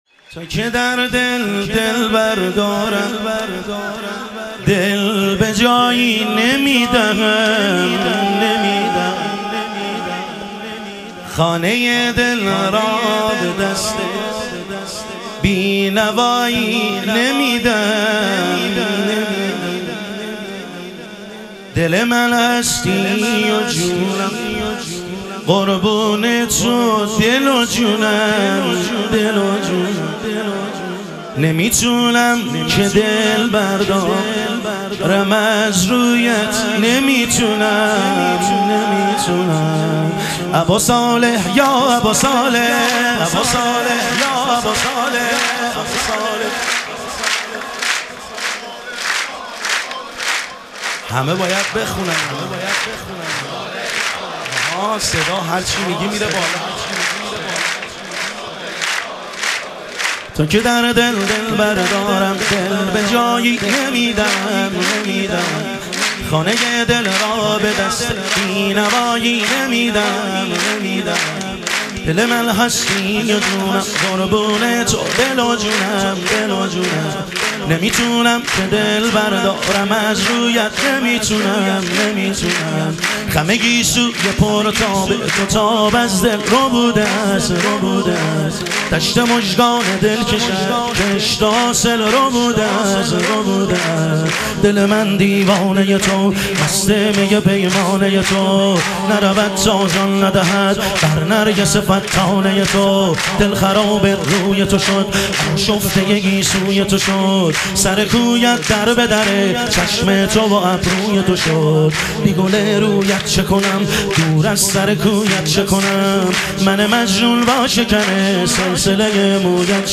ظهور وجود مقدس حضرت مهدی علیه السلام - شور